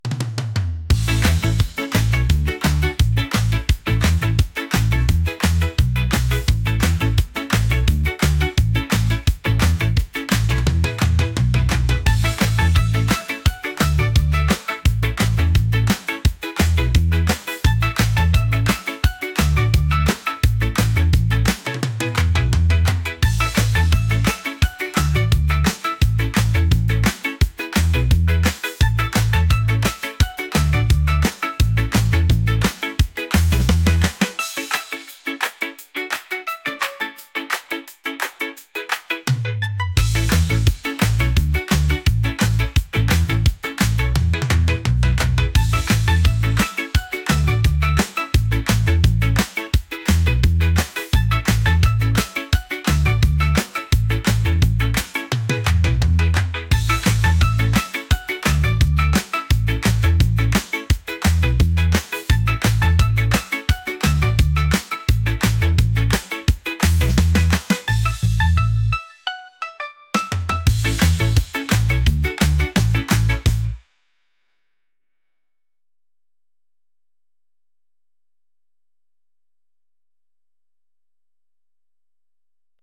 catchy | reggae | upbeat